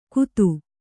♪ kutu